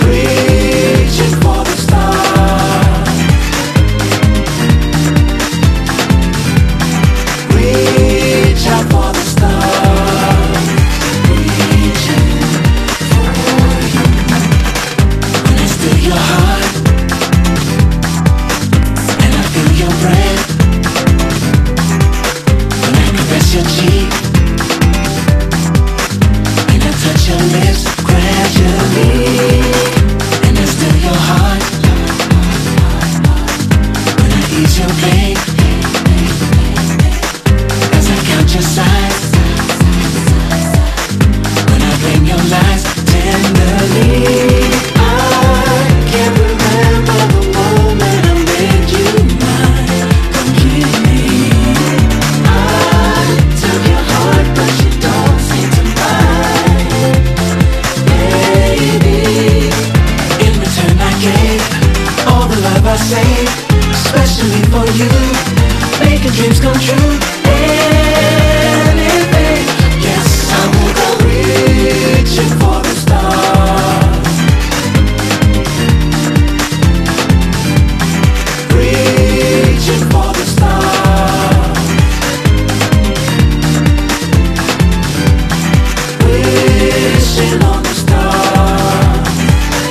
JAPANESE / 80'S / NEO GS / CHRISTMAS